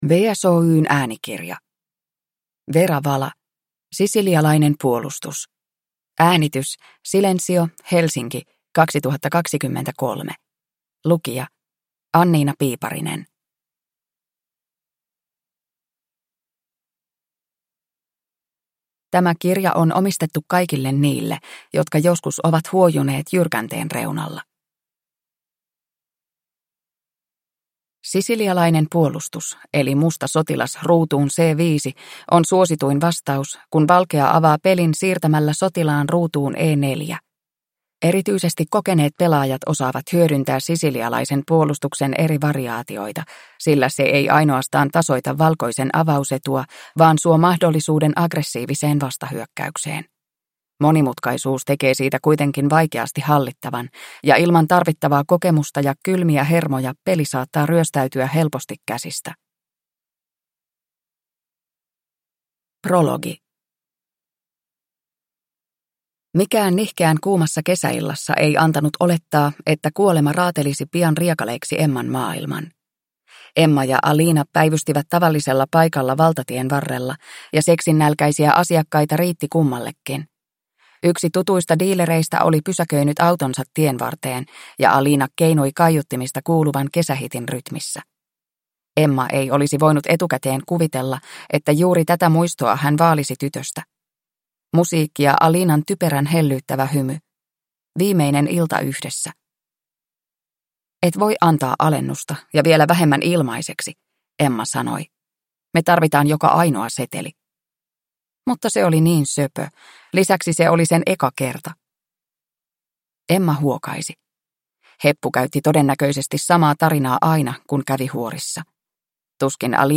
Sisilialainen puolustus – Ljudbok – Laddas ner